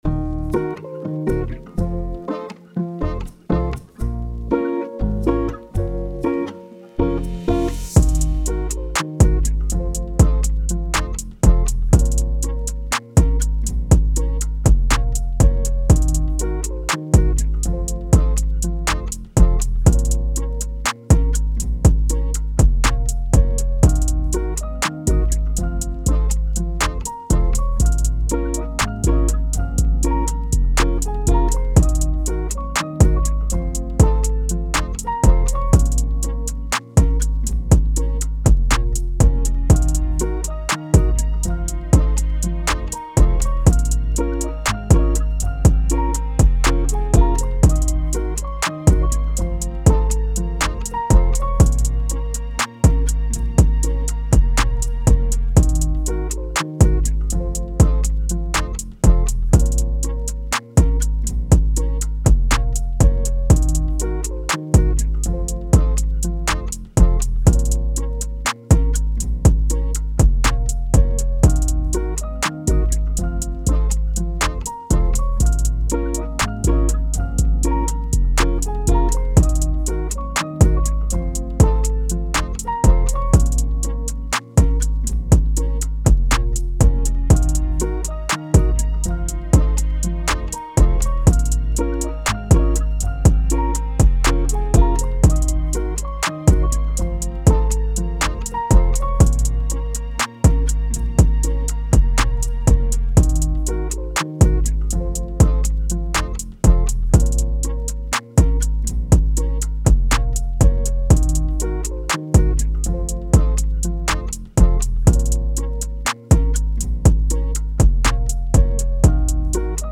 بیت سبک رپ های روسی: